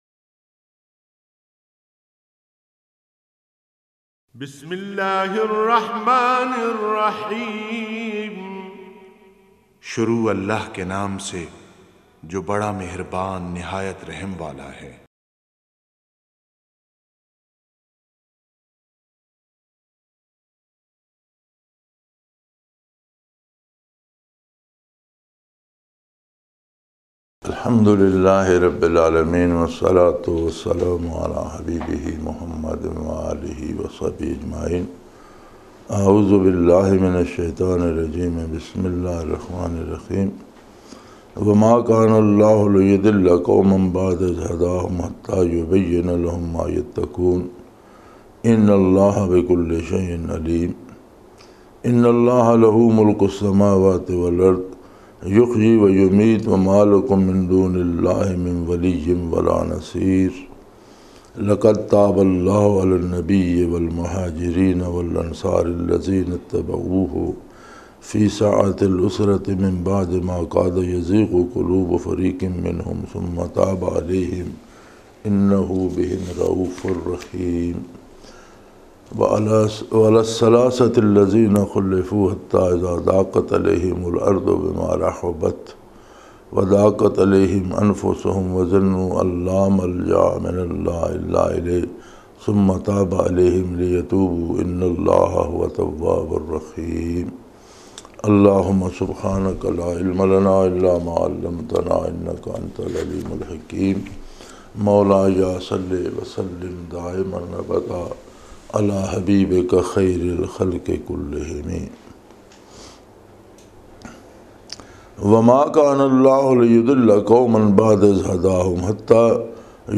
Punjabi Tafseer in Munara, Chakwal, Pakistan